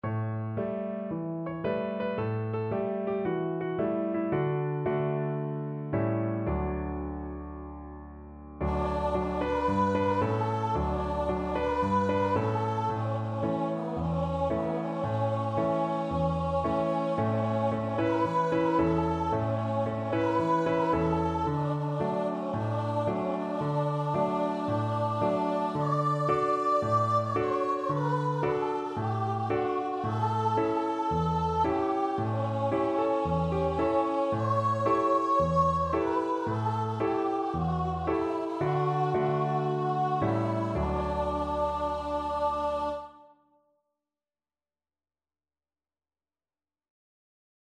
4/4 (View more 4/4 Music)
Cheerfully! =c.112
Traditional (View more Traditional Voice Music)